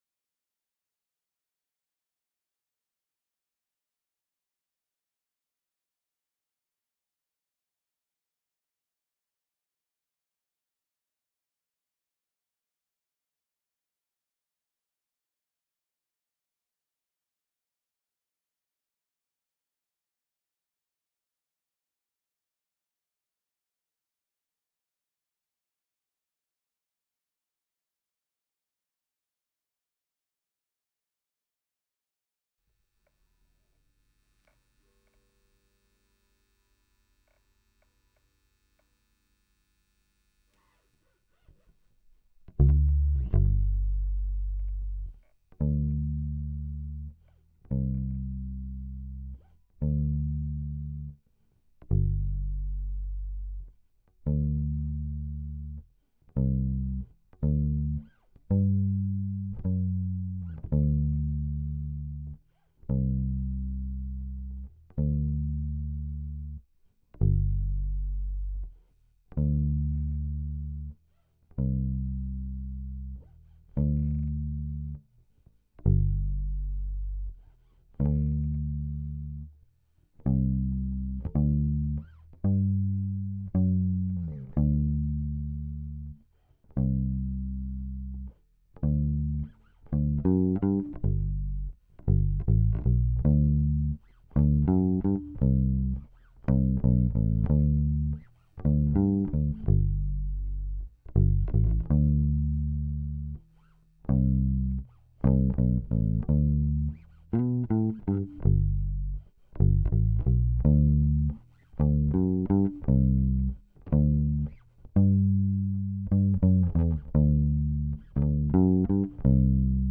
bassd.wav